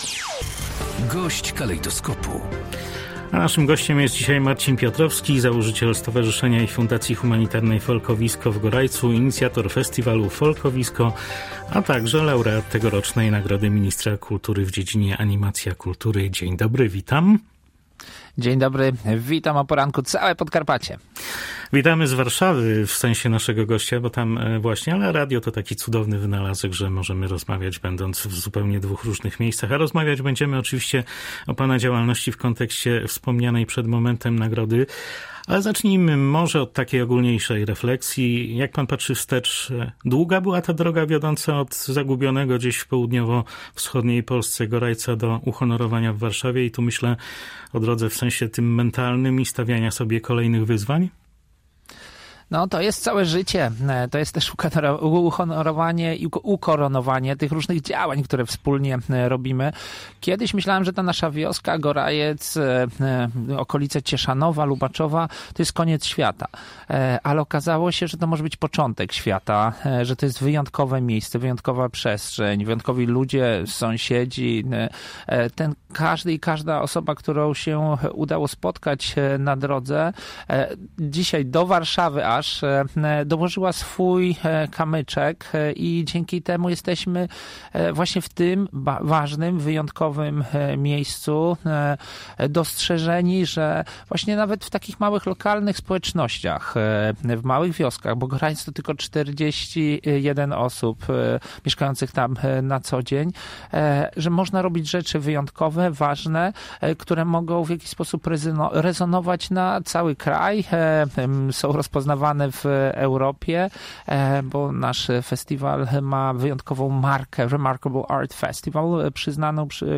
– podkreśla gość Polskiego Radia Rzeszów.